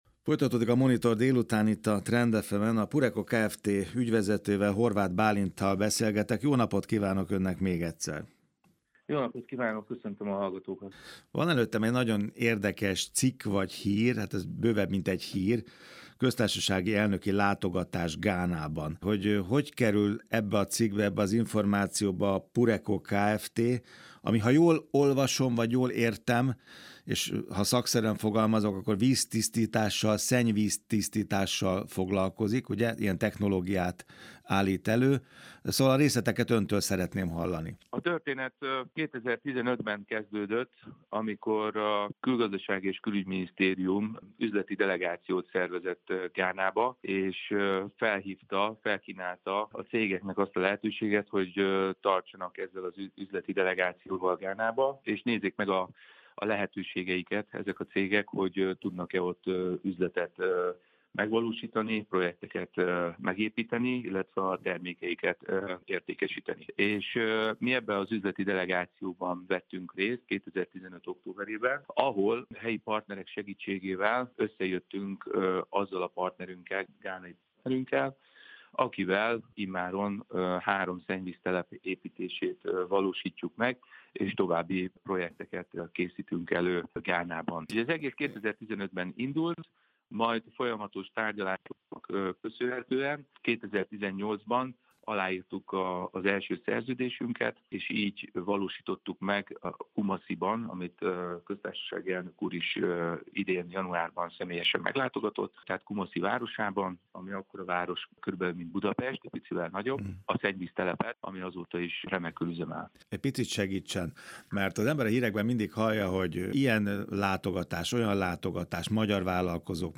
Minderről részlesen hallhat a beszélgetésben..